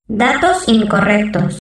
voz nș 0139